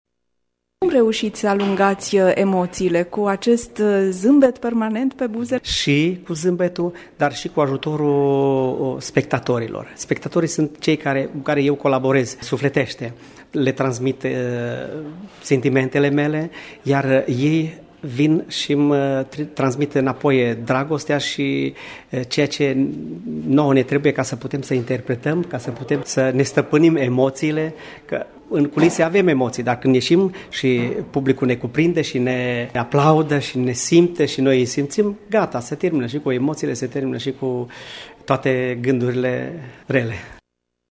Colegi de cânt, câţiva prieteni- invitaţi şi publicul fidel l-au recompensat cu ce au avut ei mai bun: aplauze şi flori, distincţii, diplome şi urări-asortate cu grijă într-un buchet de gânduri alese.